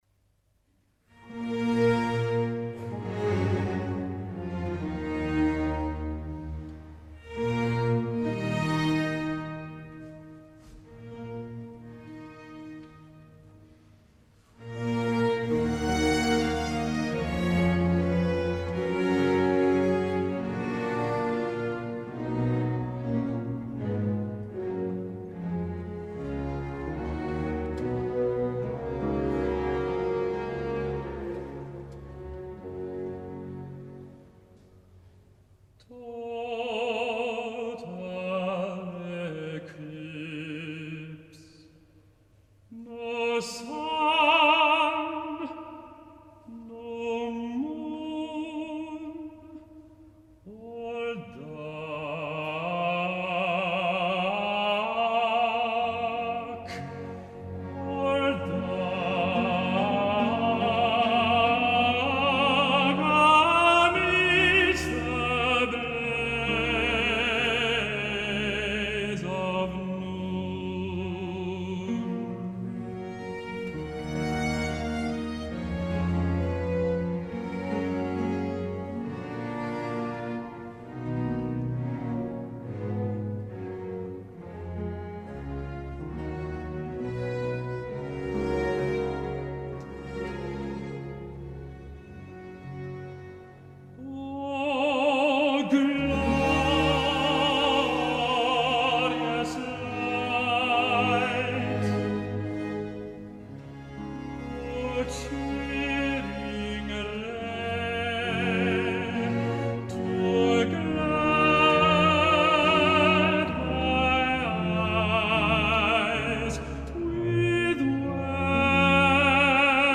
Az egyik legszebb és leghíresebb ária a főszereplő által énekelt „Total eclipse” (teljes elsötétülés) című ária.
handel-total-eclipse-oratorio-samson.mp3